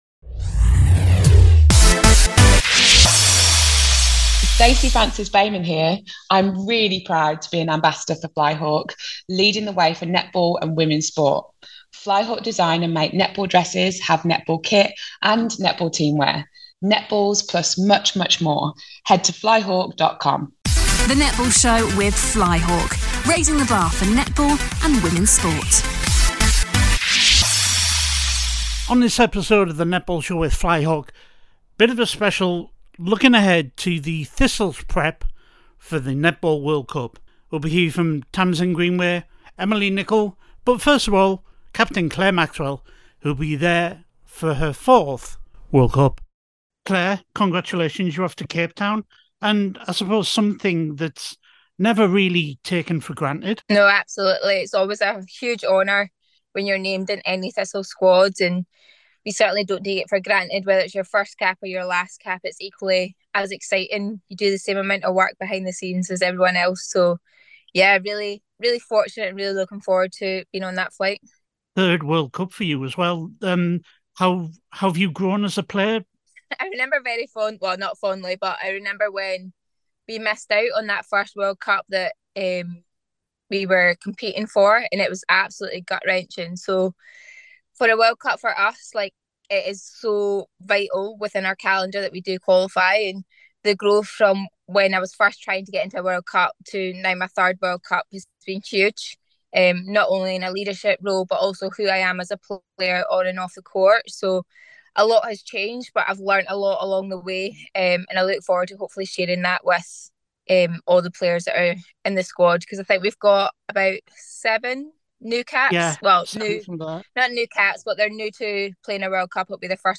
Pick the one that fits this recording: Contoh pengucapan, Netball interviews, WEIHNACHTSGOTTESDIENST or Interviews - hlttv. Netball interviews